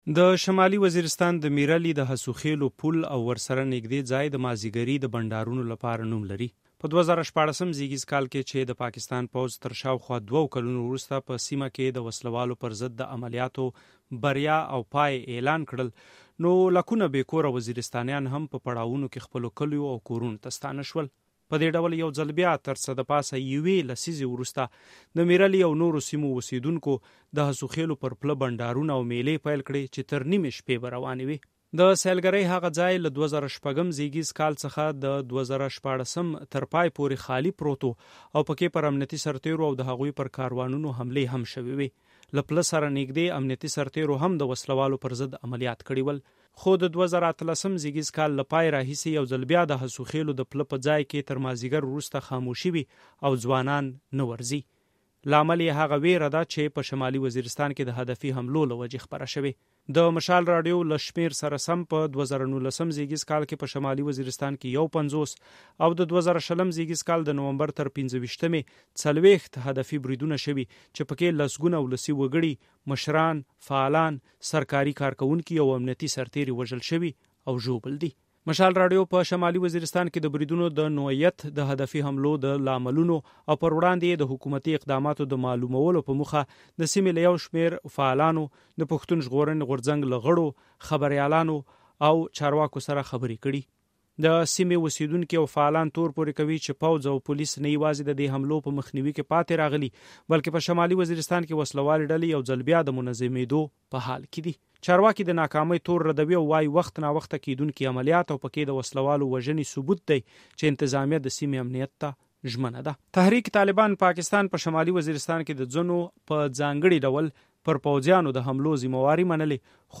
تفصیلي فیچر رپورټ واورئ